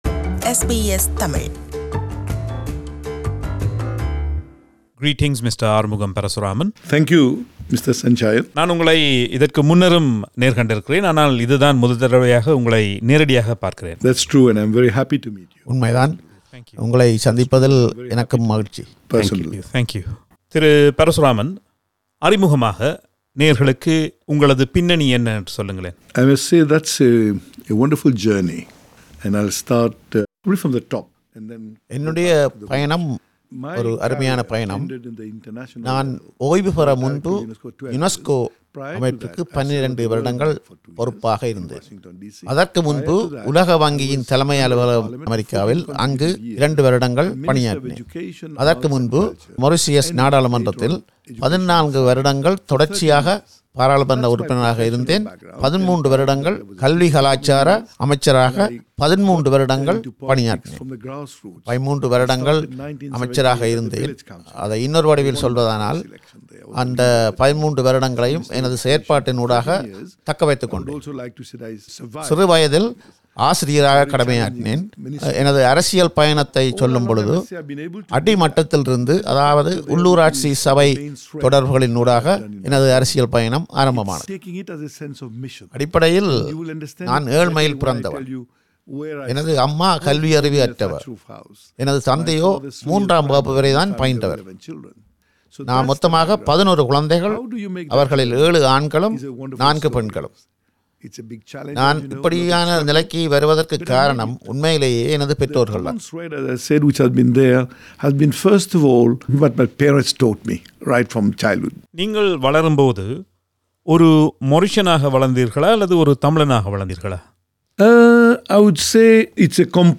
மொறிஸியஸ் நாட்டின் கல்வி, கலாச்சாரம் மற்றும் விஞ்ஞானத்துறையின் அமைச்சராகக் கடமையாற்றிய தமிழர் ஆறுமுகம் பரசுராமன் ஓய்வு பெற்ற பின்னரும் ஓய்ந்திருக்கவில்லை. அண்மையில் ஆஸ்திரேலியாவின் சிட்னி நகருக்கு வந்திருந்த அவரை நேர்கண்டு உரையாடுகிறார்
அவர் ஆங்கிலத்தில் வழங்கிய பதில்களுக்குத் தமிழில் குரல் கொடுத்தவர்